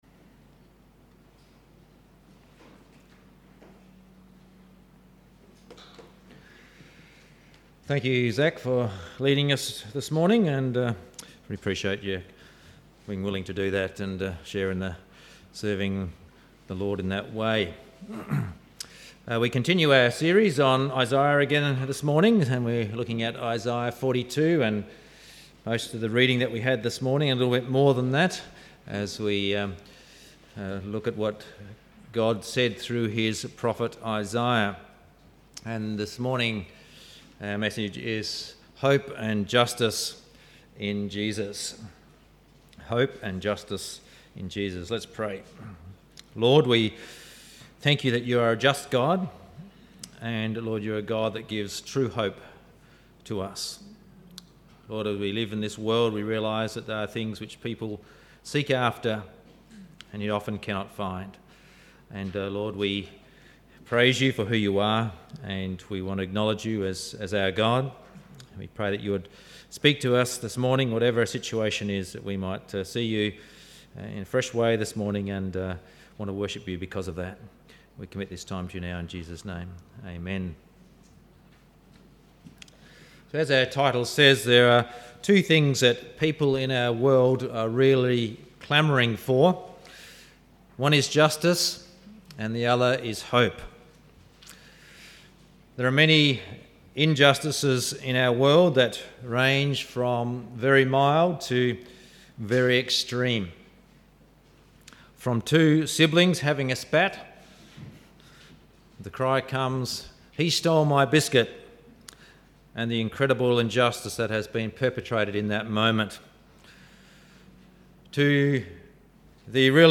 Sunday Service Audio 29/10/17